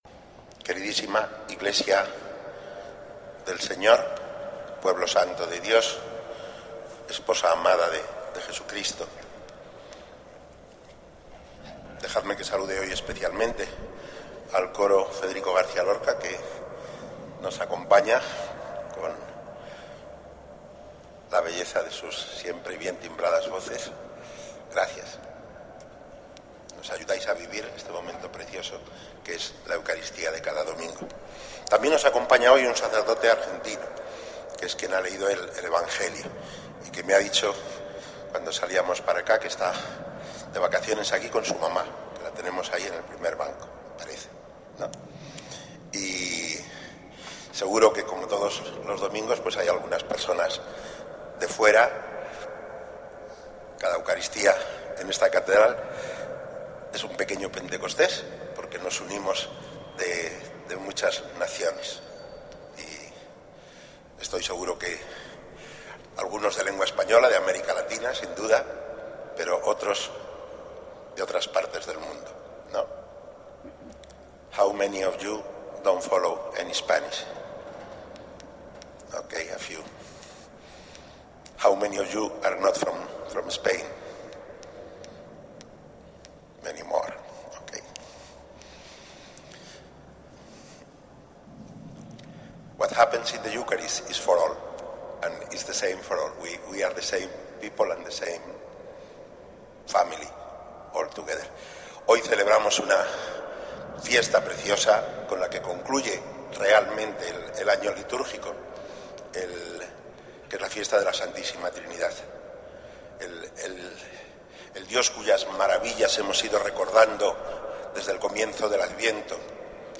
Homilía de Mons. Javier Martínez en la Eucaristía jubilar en el Año de la Misericordia para las personas que colaboran y trabajan con instituciones de caridad, en el día de la Solemnidad del Santísimo Cuerpo y Sangre de Cristo y, por tanto, Día de la Caridad.